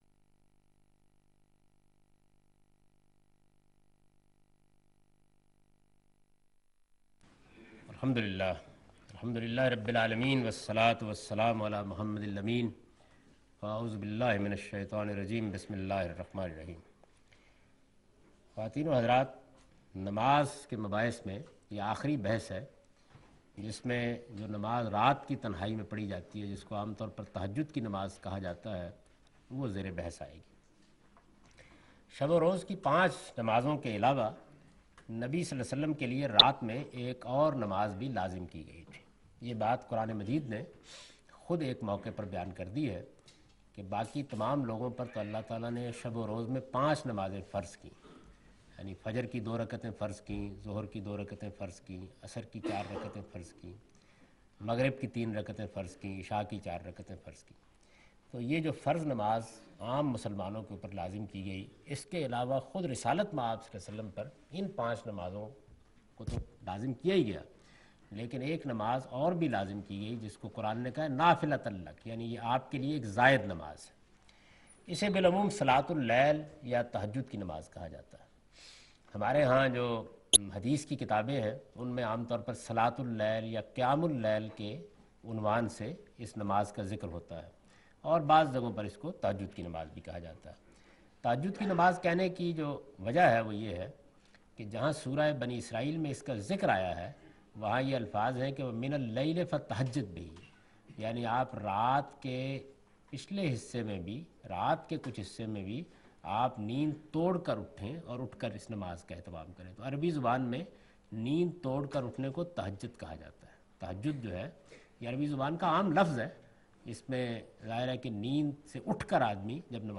In this lecture series he teaches 'The shari'ah of worship rituals'. In this sitting he is teaching the optional prayer.